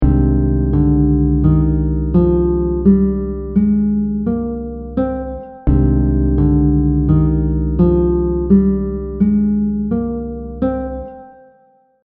The audio examples will repeat the scale over a chord so you can get a better grasp of the sound of each mode.
• Mood / emotion: melancholic, mournful, contemplative 🥺
• Characteristic note: flat 6
C Aeolian scale audio example